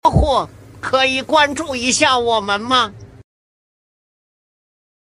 Cute, Adorable And Naughty Cat Sound Effects Free Download